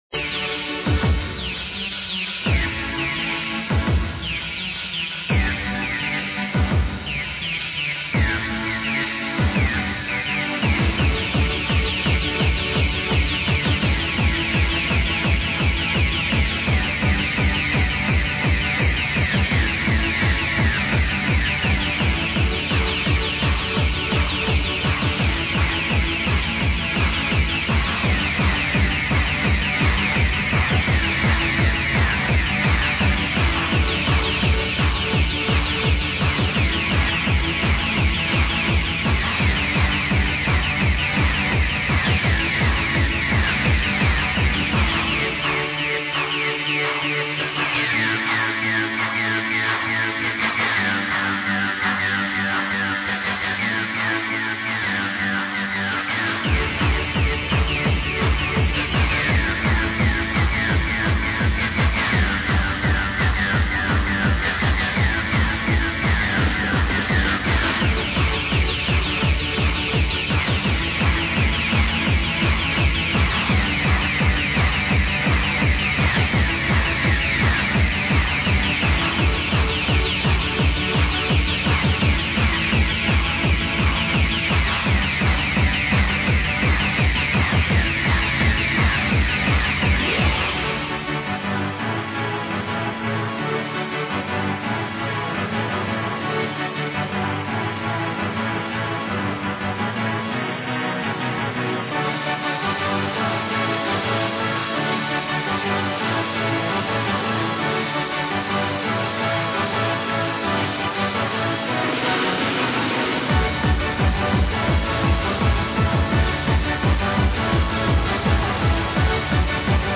i haven't the foggiest clue what that is, but i do know it sounds a little like happy hardcore